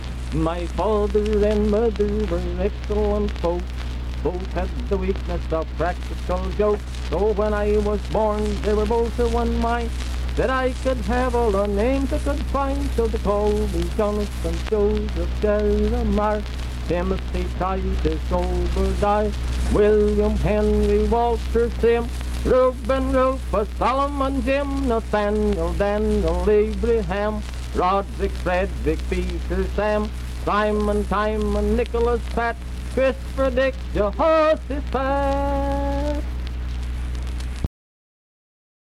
Unaccompanied vocal performance
Humor and Nonsense, Dance, Game, and Party Songs
Voice (sung)
Wood County (W. Va.), Vienna (W. Va.)